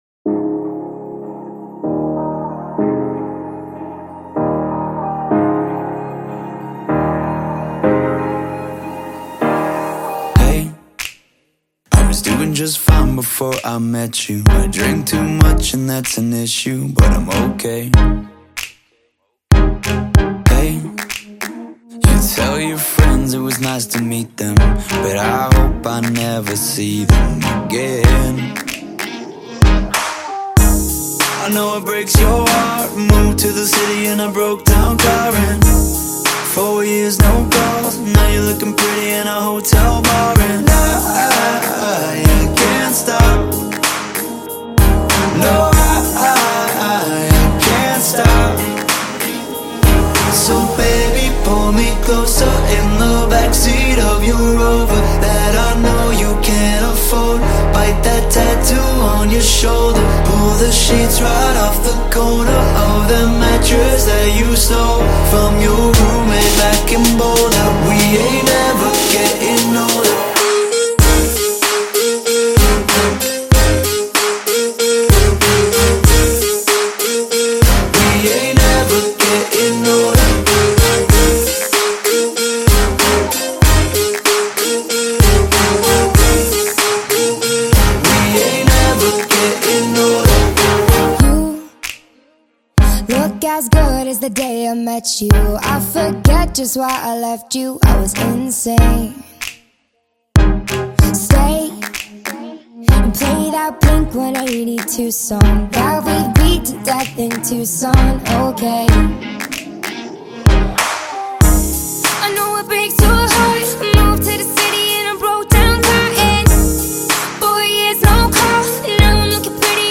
Genre:Dance-pop & Pop